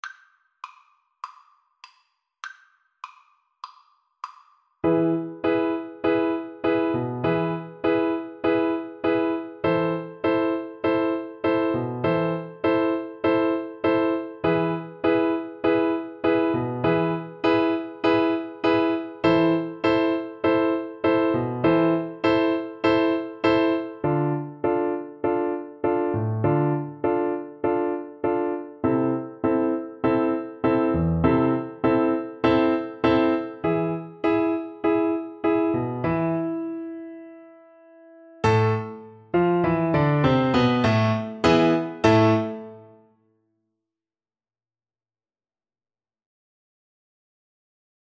4/4 (View more 4/4 Music)
Tempo di Tango